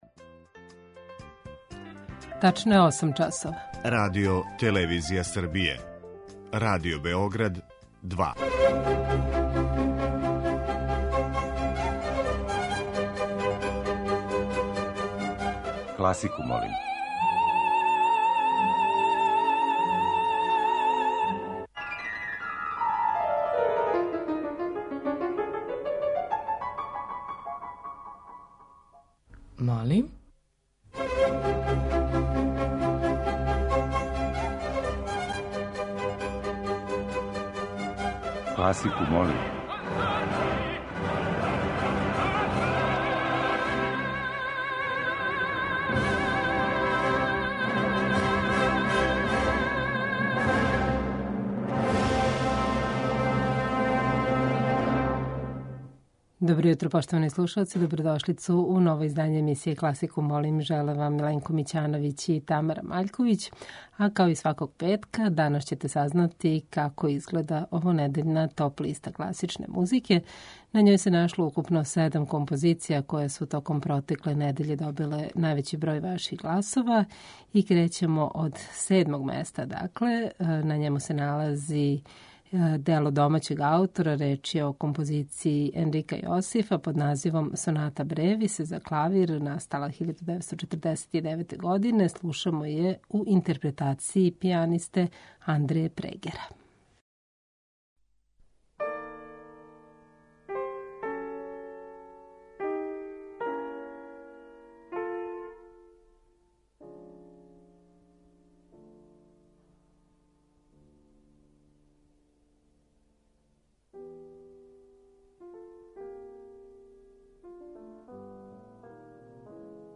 Уживо вођена емисија, окренута широком кругу љубитеља музике, разноврсног је садржаја, који се огледа у пођеднакој заступљености свих музичких стилова, епоха и жанрова.